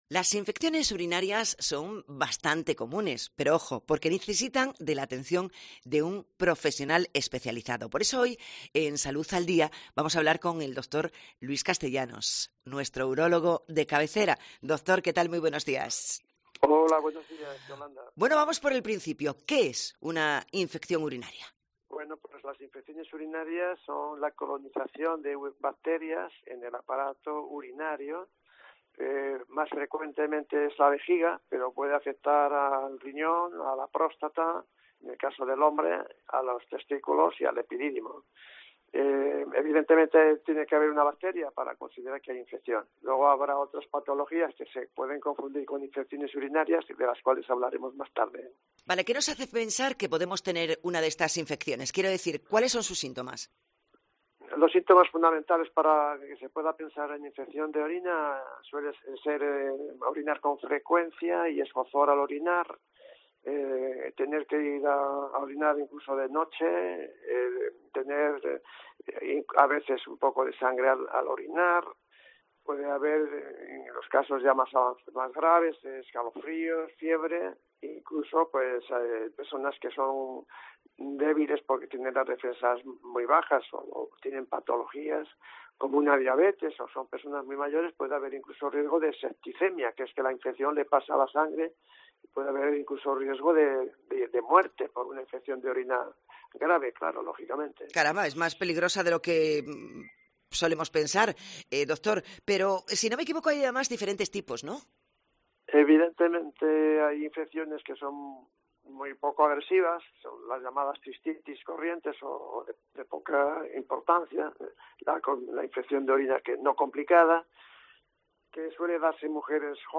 Salud al Día en COPE: entrevista